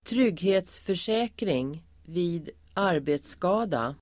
Uttal: [²tr'yg:he:tsför_sä:kring vi:d ²'ar:be:tska:da]